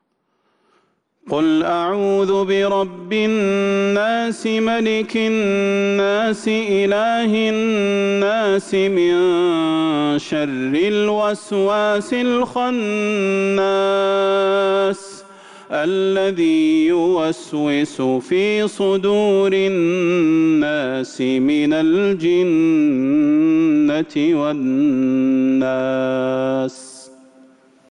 سورة الناس | رجب 1447هـ > السور المكتملة للشيخ صلاح البدير من الحرم النبوي 🕌 > السور المكتملة 🕌 > المزيد - تلاوات الحرمين